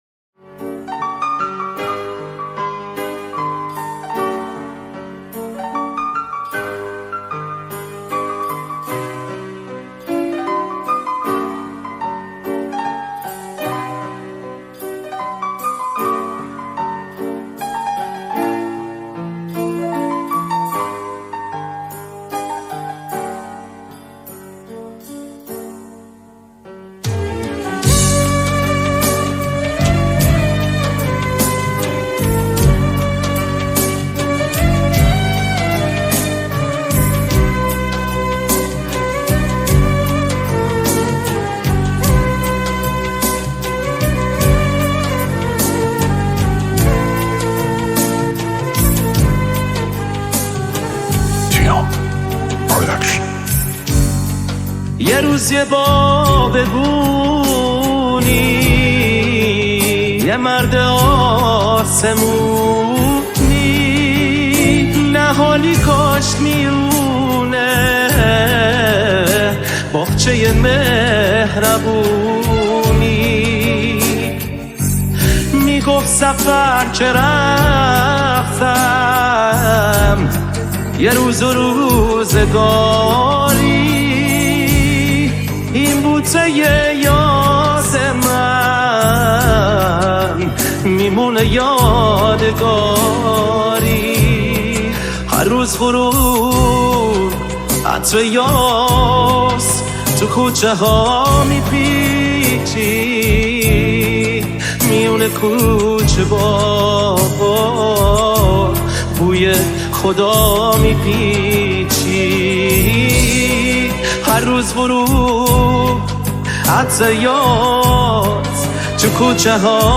آهنگ پاپ ایرانی